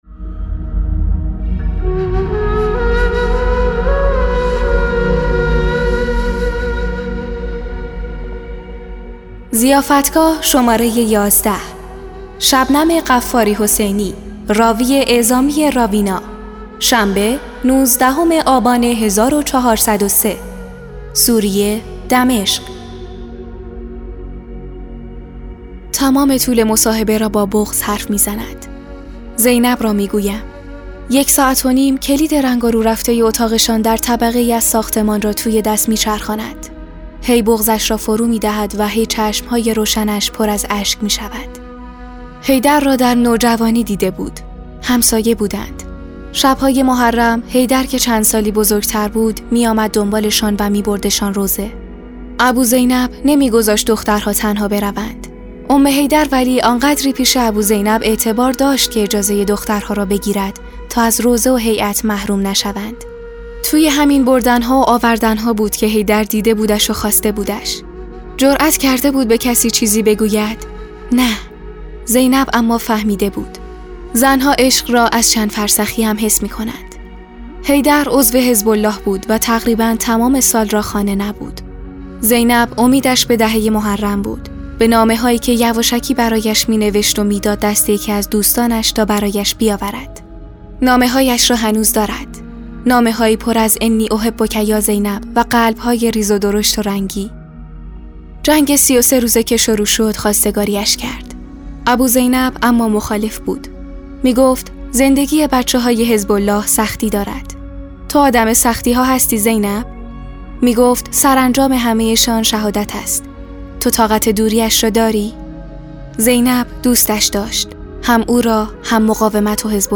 تمام طول مصاحبه را با بغض حرف می‌زند...